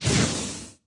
Media:Wizard_base_atk_1.wav 攻击音效 atk 初级和经典及以上形态攻击音效
Wizard_base_atk_1.wav